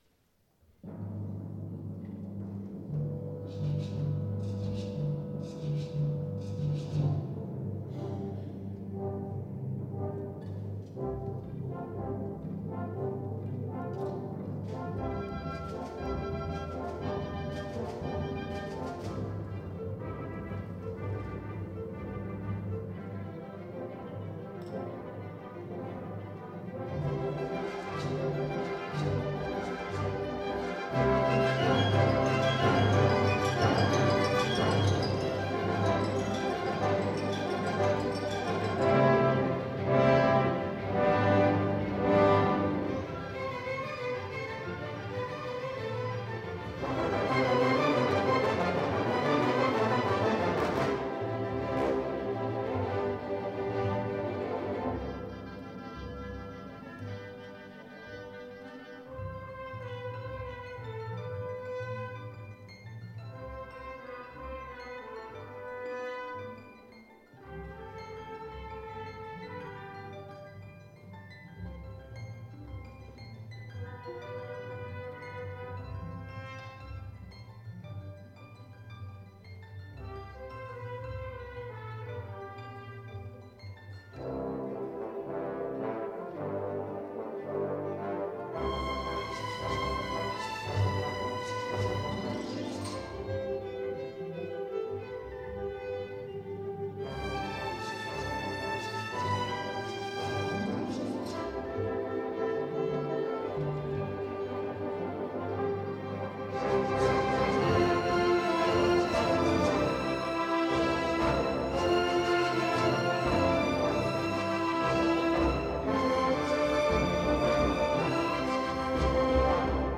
Symphonia Nova Orchester Concerts 1998